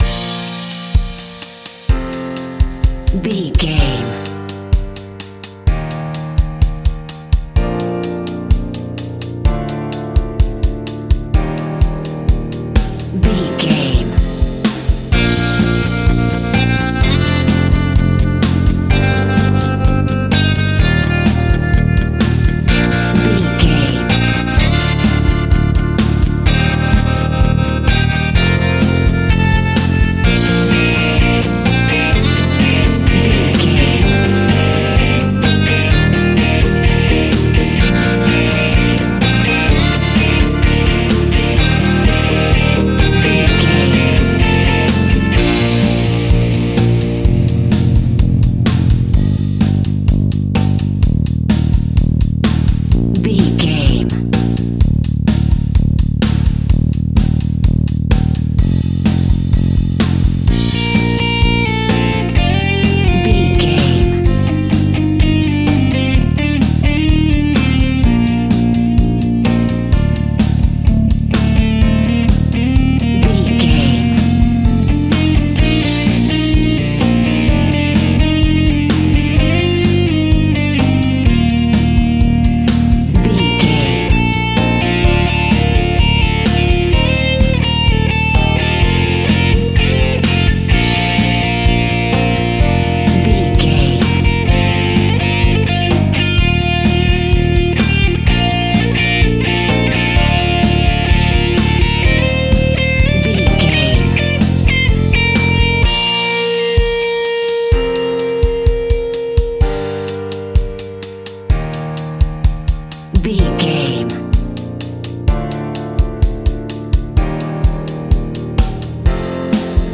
Ionian/Major
B♭
indie pop
energetic
uplifting
cheesy
instrumentals
guitars
bass
drums
piano
organ